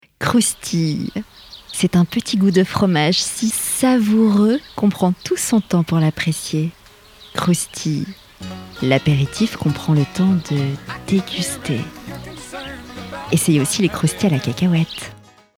Voix off
Démo pub 'croustille'